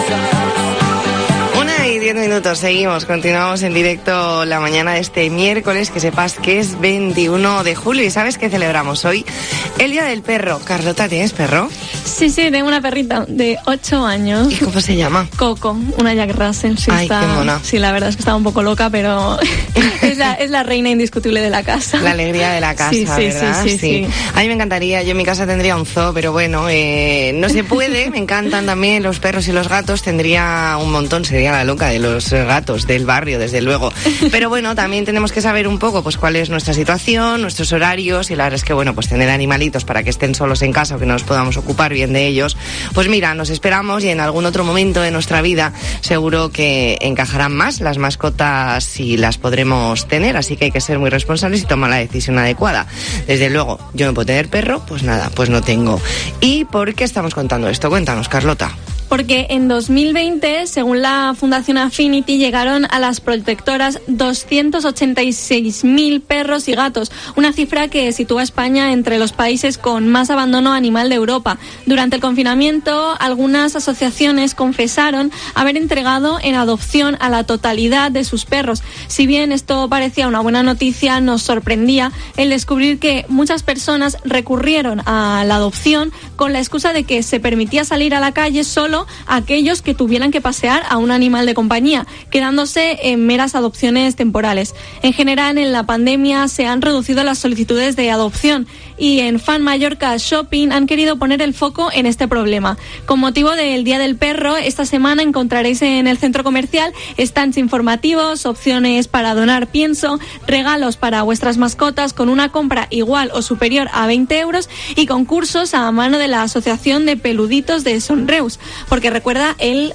Entrevista en La Mañana en COPE Más Mallorca, miércoles 21 de julio de 2021.